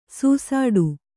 ♪ sūsāḍu